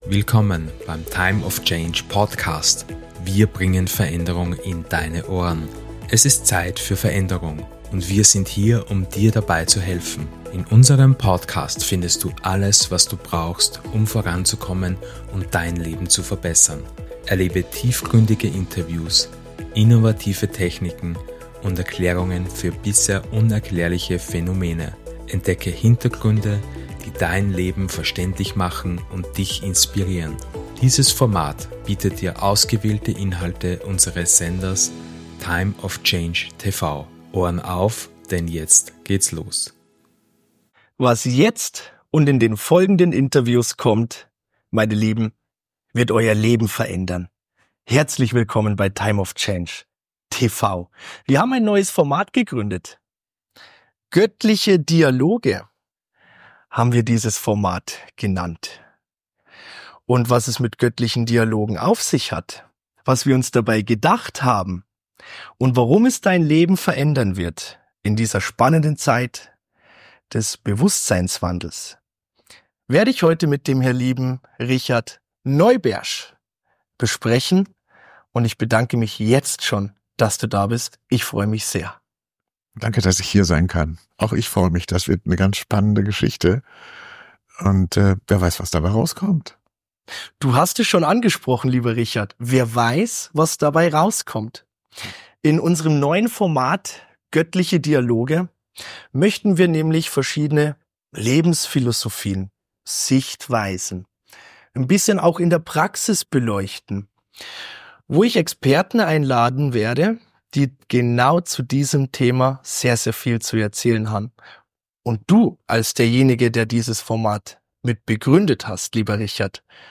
In diesem tiefgründigen Gespräch erfährst Du, wie Du Dein göttliches Selbst entdecken und in Deinem Leben integrieren kannst.